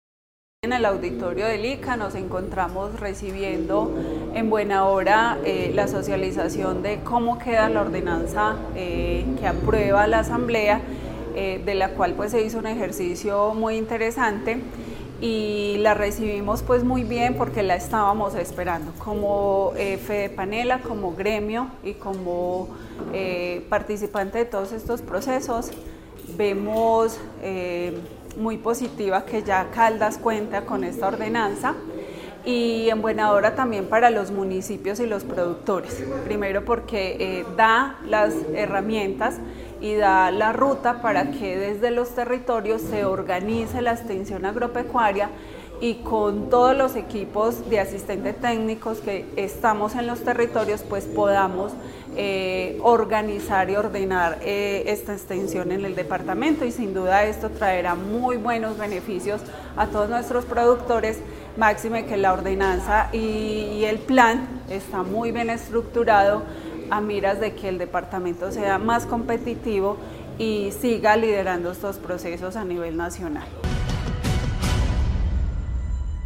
La Gobernación de Caldas, a través de su Secretaría de Agricultura y Desarrollo Rural de Caldas, llevó a cabo el tercer encuentro del año del Consejo Seccional de Desarrollo Agropecuario, Pesquero, Forestal, Comercial y de Desarrollo Rural (CONSEA) en el auditorio del Instituto Colombiano Agropecuario (ICA). En este espacio se socializó la ordenanza 1003, mediante la cual se adoptó el Plan Departamental de Extensión Agropecuaria (PDEA), una estrategia que busca fortalecer la ruralidad y abrir nuevas oportunidades de desarrollo para el campo caldense.